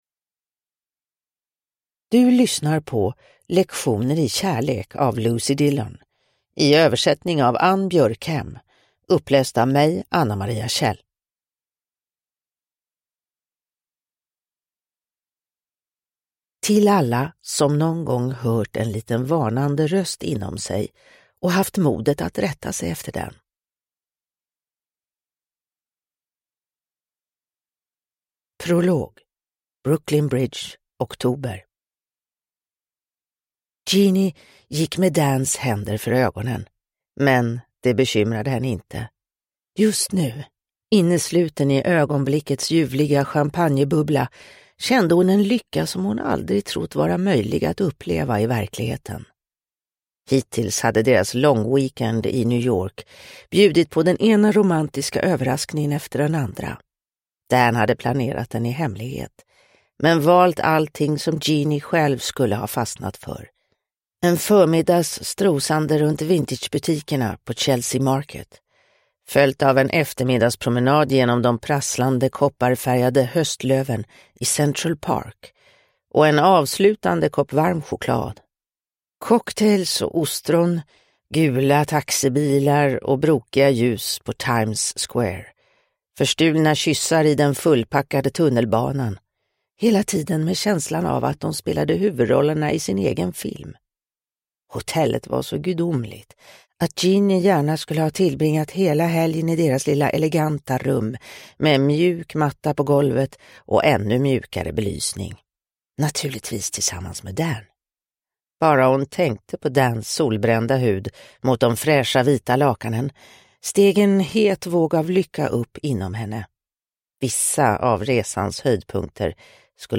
Lektioner i kärlek – Ljudbok – Laddas ner